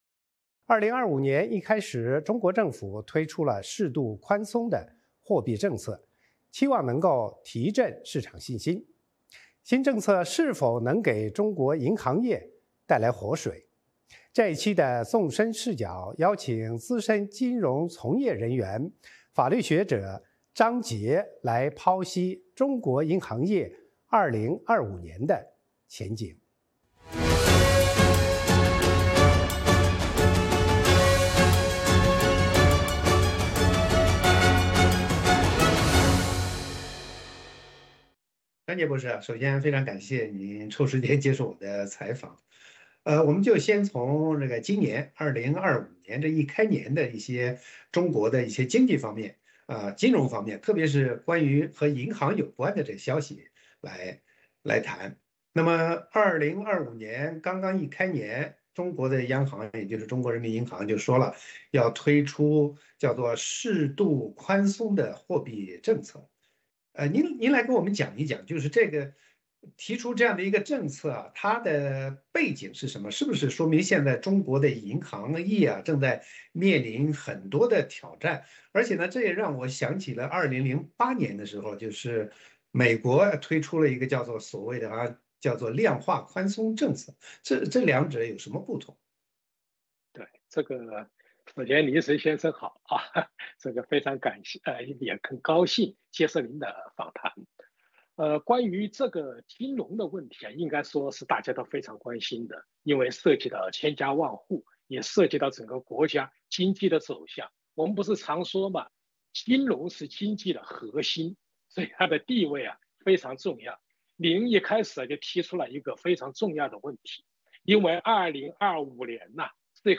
《纵深视角》节目进行一系列人物专访，受访者发表的评论不代表美国之音的立场 。